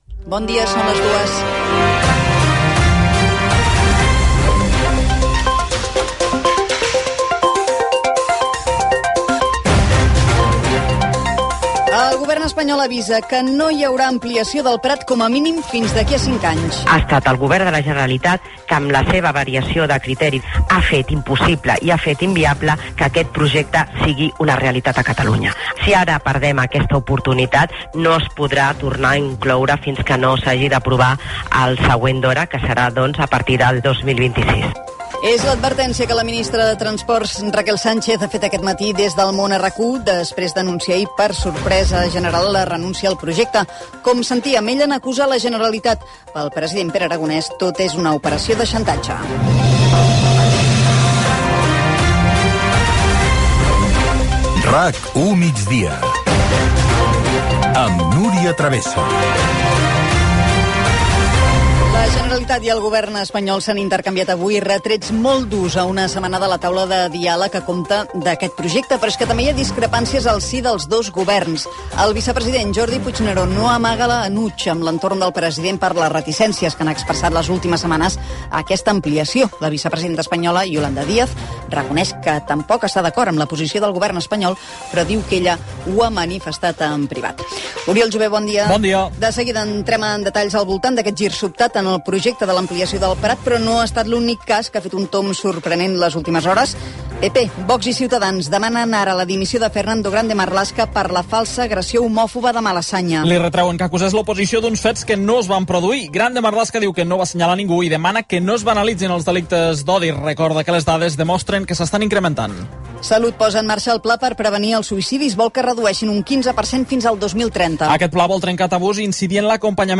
Hora, ajornada l'ampliació de l'Aeroport del Prat, indicatiu del programa, resum informatiu, esports, el temps, hora, indicatiu, ajornada l'ampliació de l'Aeroport del Prat, demanda de nova estació del tren de l'alta velocitat a Tarragona, les agressions a persones LGTBI Gènere radiofònic Informatiu